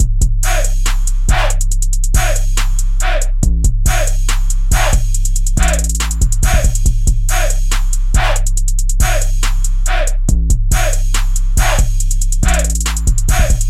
硬式陷阱鼓
描述：只是一个808和惊悚的节拍。 如果你使用它，请分享作品。
Tag: 140 bpm Trap Loops Drum Loops 2.31 MB wav Key : Unknown